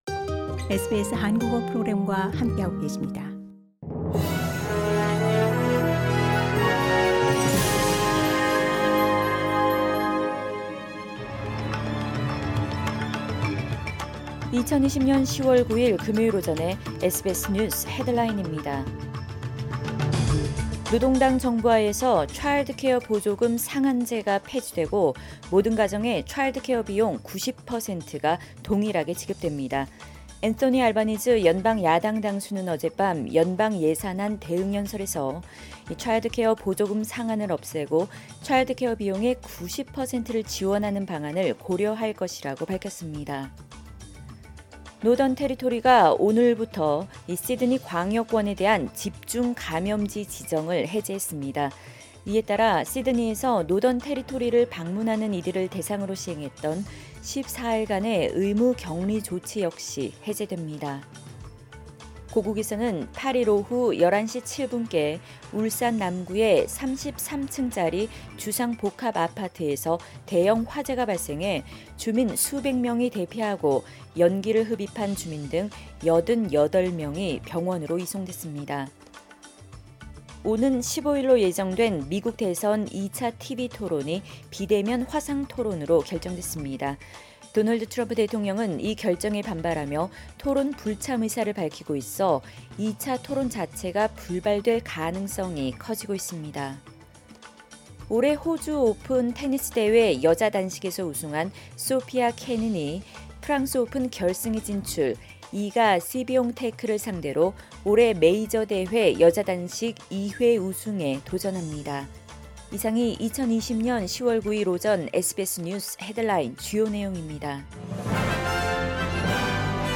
SBS News Headlines...2020년 10월 9일 오전 주요 뉴스